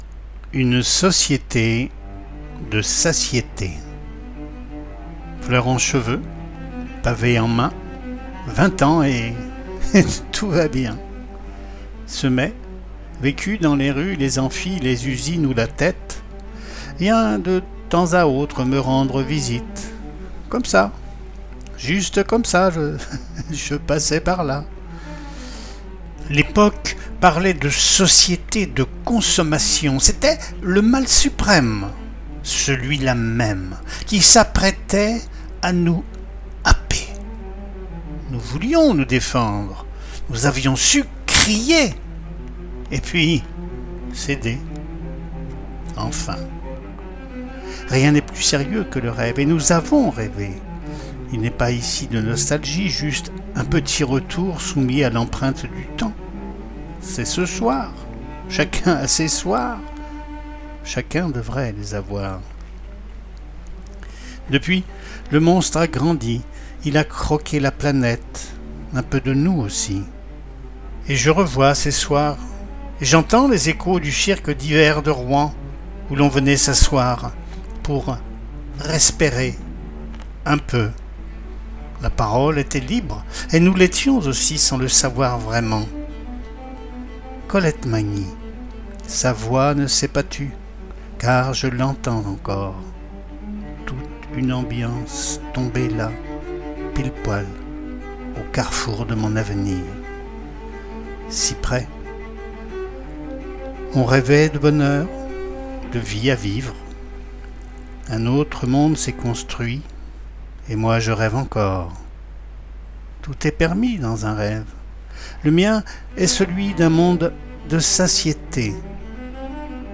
Lecture à voix haute : Une société de satiété   audio